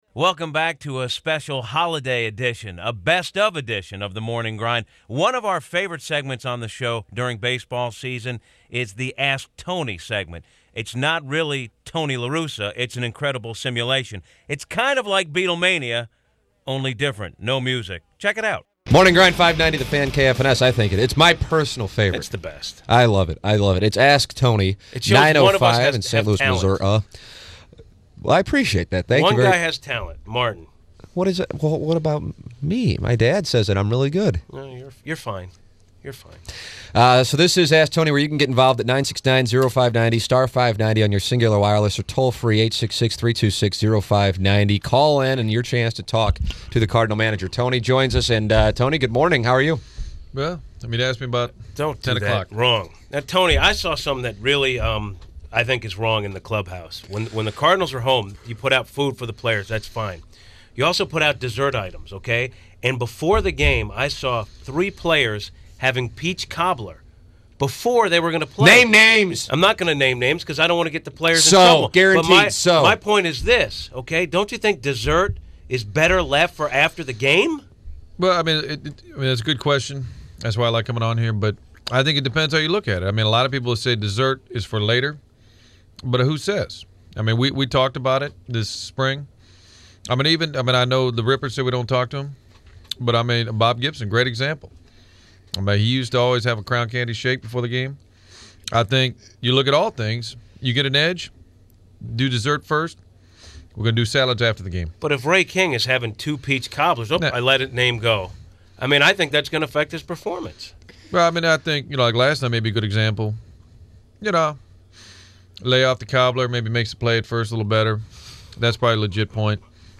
A Morning Grind “best of” show that originally aired the day after Thanksgiving, filled with classic clips.